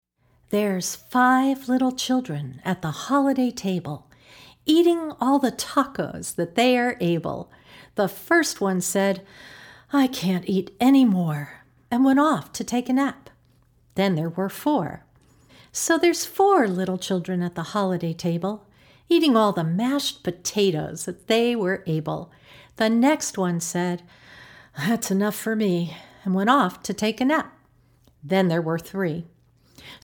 A wonderfully engaging fingerplay!